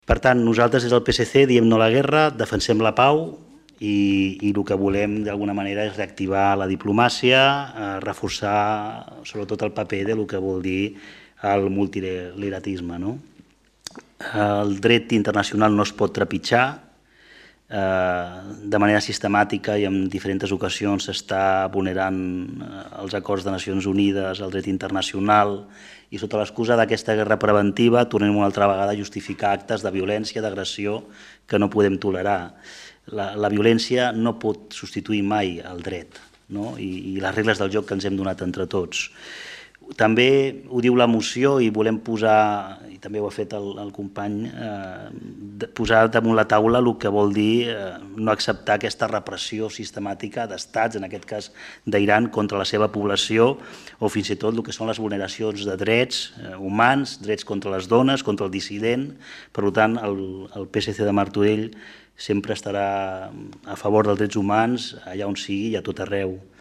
Javier González, regidor de Transició Digital i Sostenible